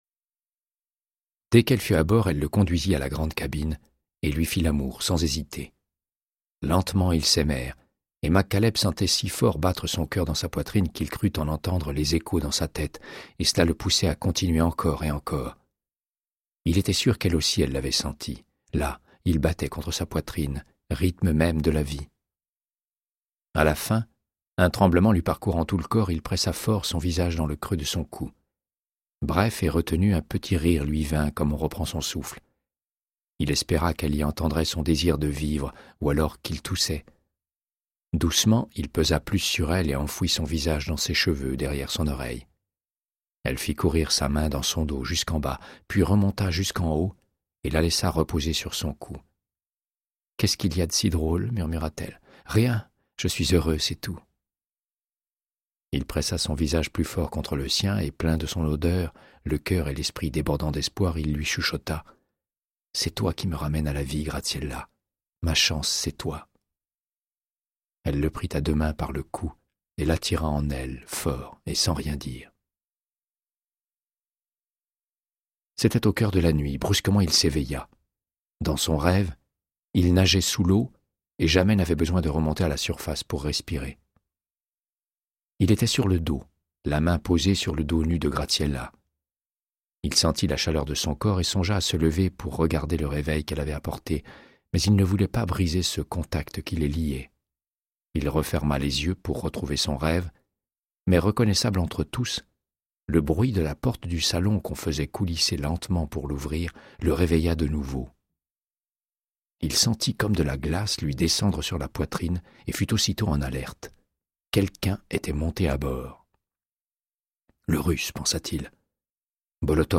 Audiobook = Créance de sang, de Michael Connelly - 106